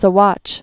(sə-wŏch)